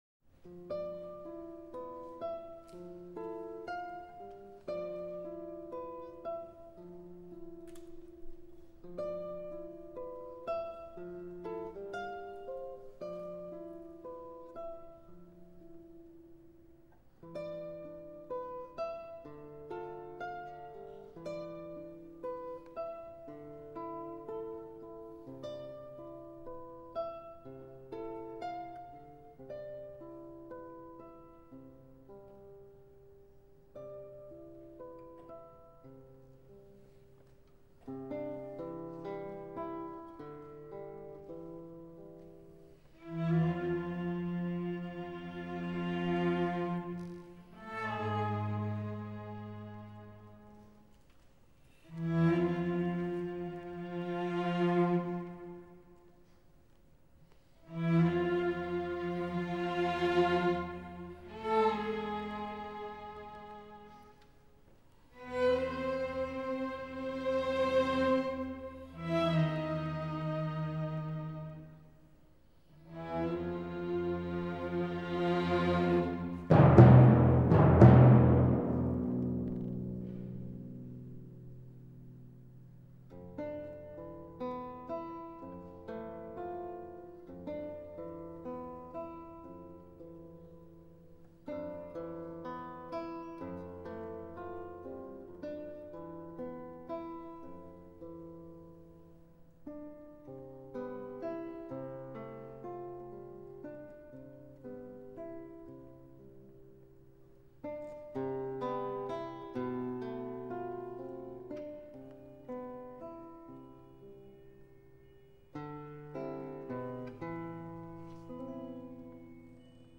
(Toccato-molto vivace)